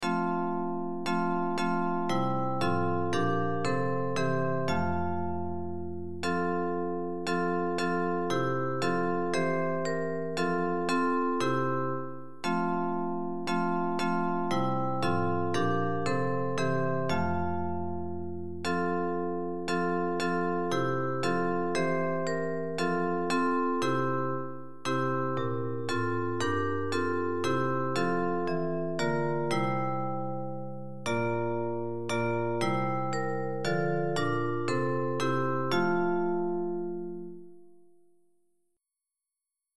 Hymns of praise
Bells Version